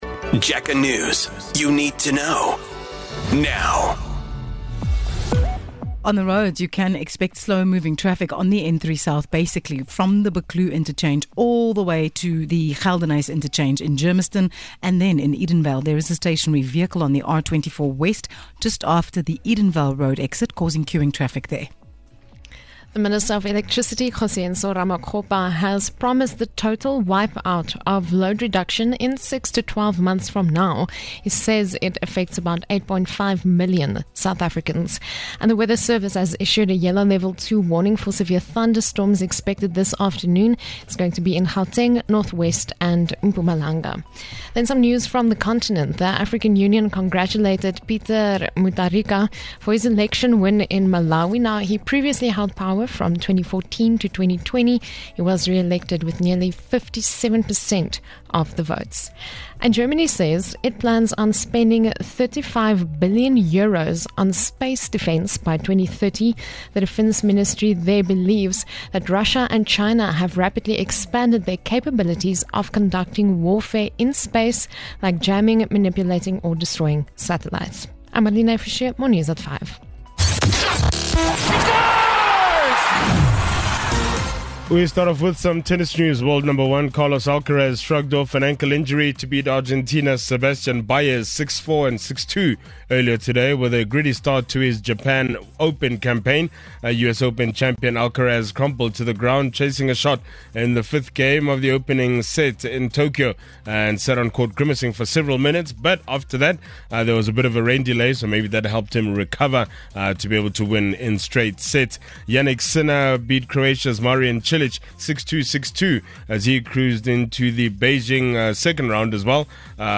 Here's your latest Jacaranda FM News bulletin.